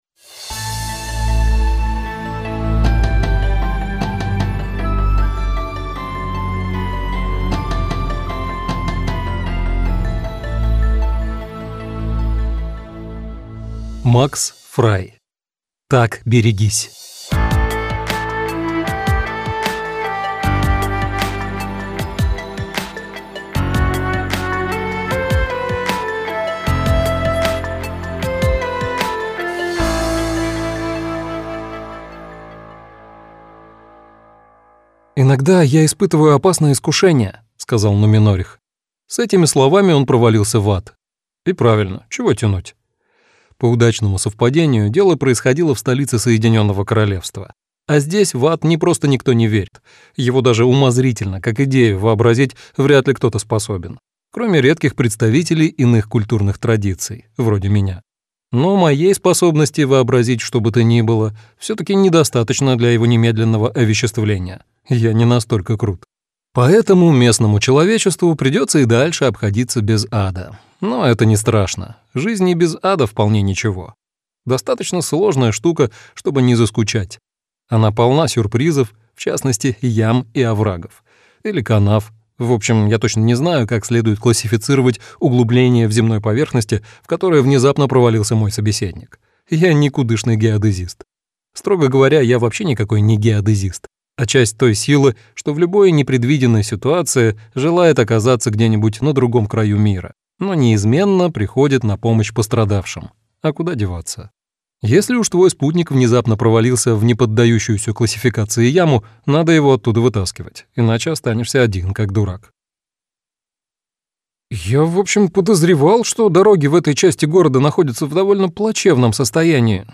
Аудиокнига Так берегись - купить, скачать и слушать онлайн | КнигоПоиск